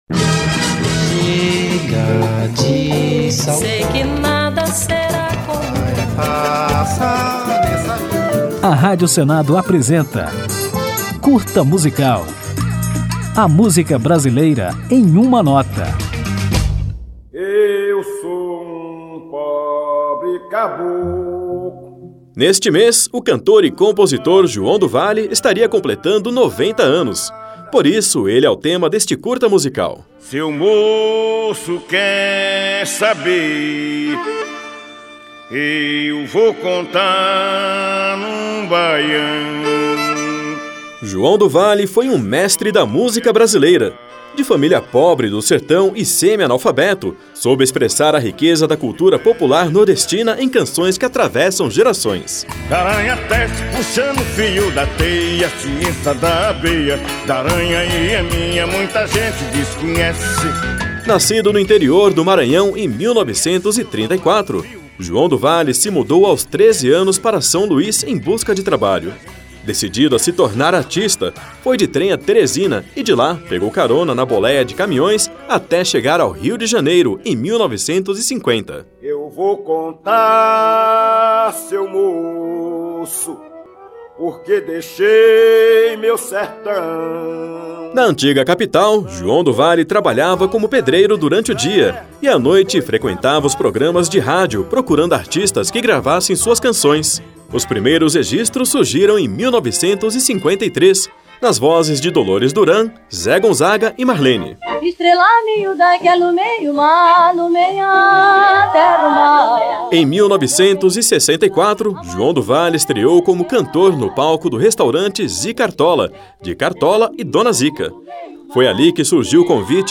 Neste mês, comemoramos os 90 anos de nascimento de João do Vale, músico maranhense que soube expressar a riqueza da cultura popular nordestina em canções que atravessam gerações, como Pisa na Fulô, Coroné Antônio Bento e O Canto da Ema. Ao final da homenagem, vamos ouvir João do Vale em um de seus maiores sucessos, a música Carcará, numa gravação com participação de Chico Buarque.